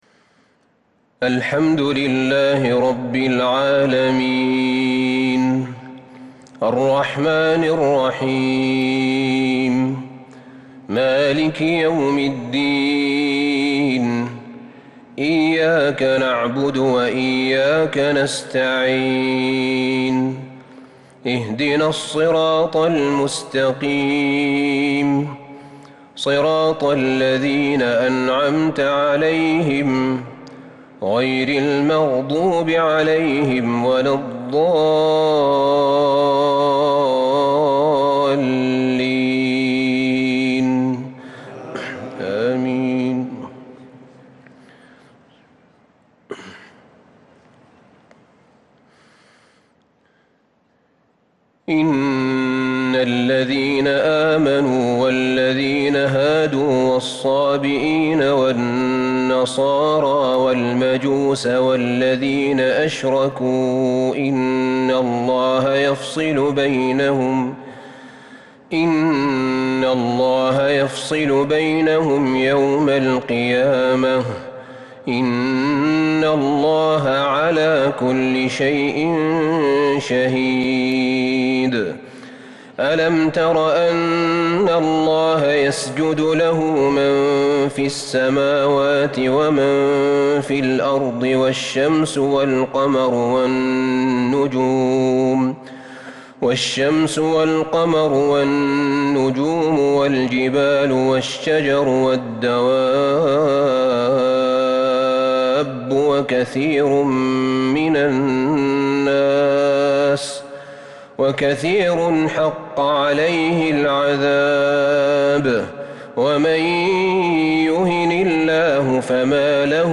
تراويح ليلة 22 رمضان 1442هـ من سورة الحج {17_ 78 }المؤمنون {1-22} | Taraweeh 22 th night Ramadan 1442H > تراويح الحرم النبوي عام 1442 🕌 > التراويح - تلاوات الحرمين